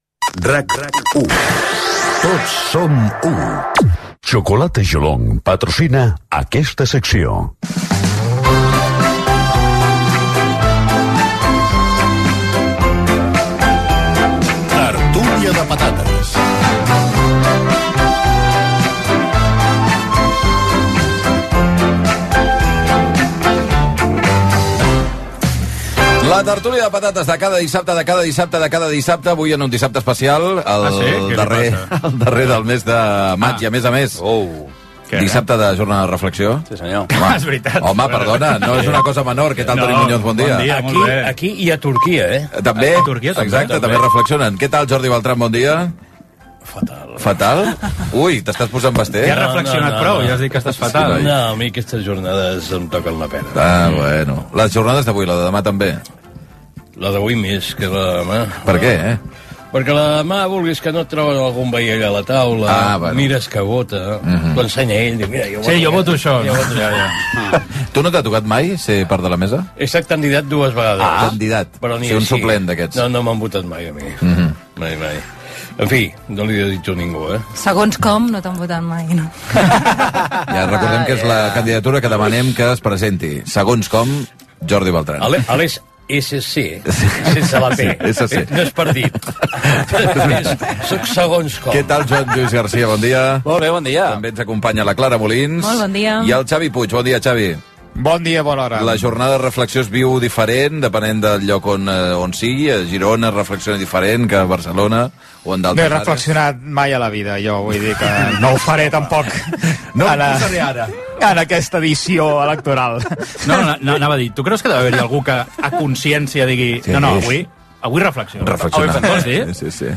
Indicatiu de l'emissora, publicitat i "Tertúlia de patates"
Gènere radiofònic Entreteniment